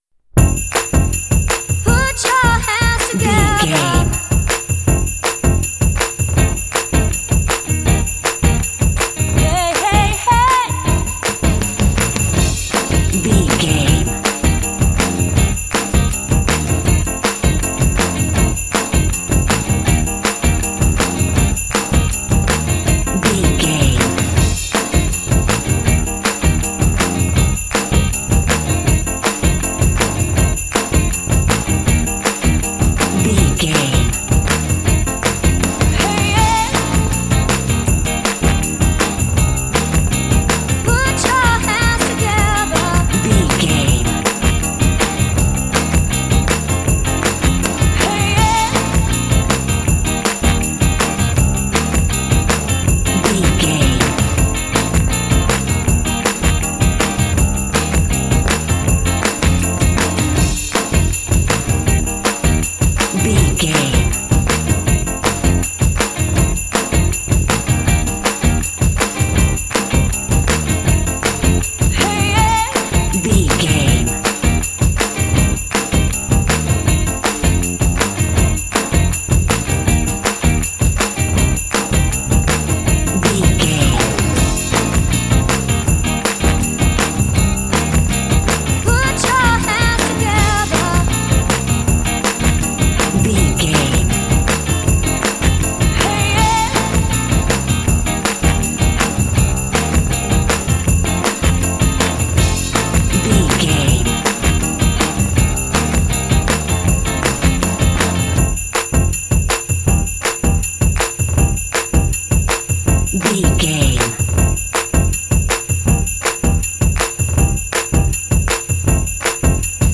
Ionian/Major
Fast
uplifting
bouncy
festive
bass guitar
electric guitar
drums
contemporary underscore